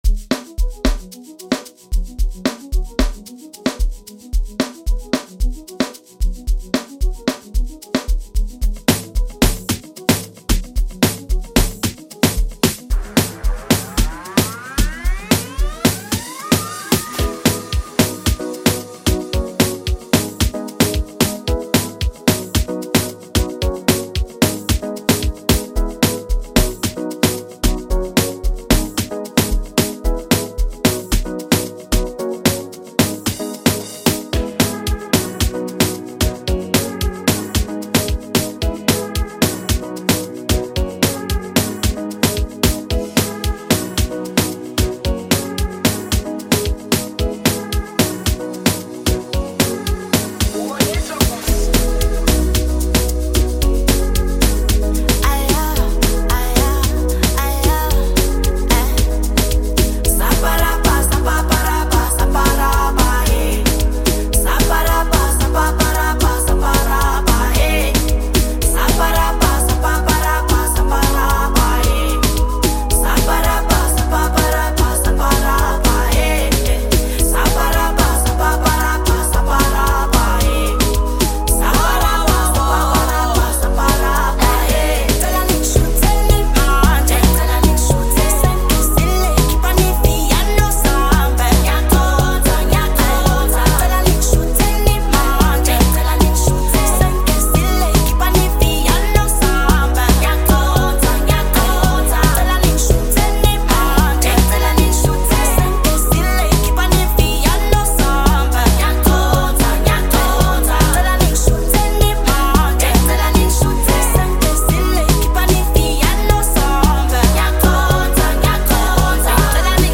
Category: Amapiano
explodes as an electrifying amapiano collaboration